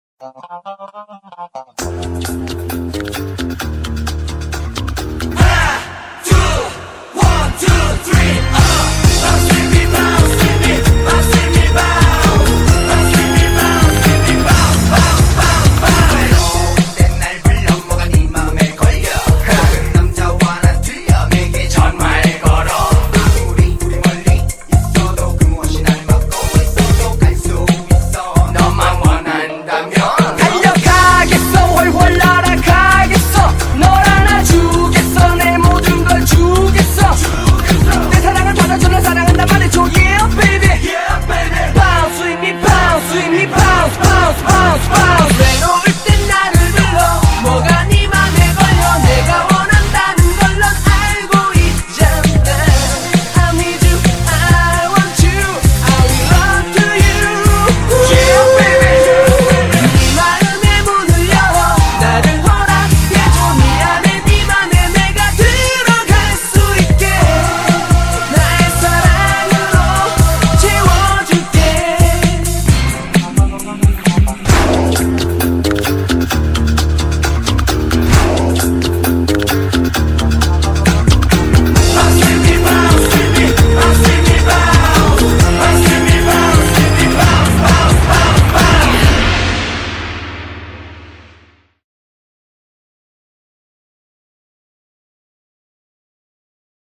BPM131--1
Audio QualityPerfect (High Quality)